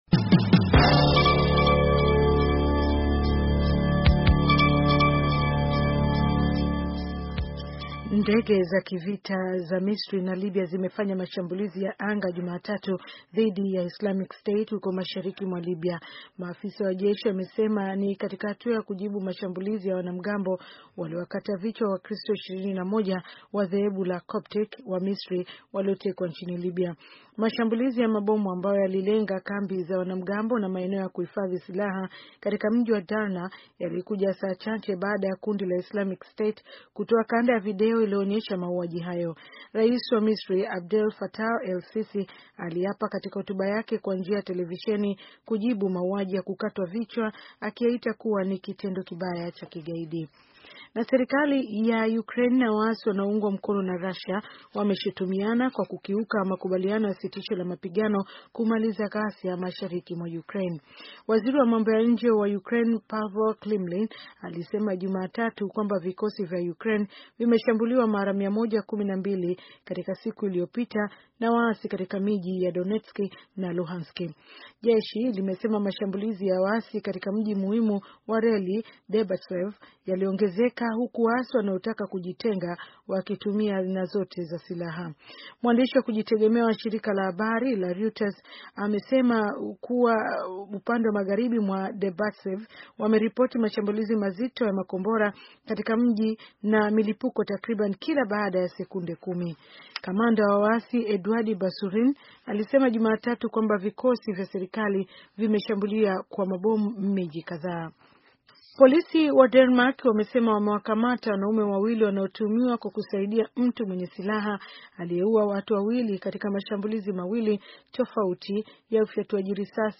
Taarifa ya habari - 4:54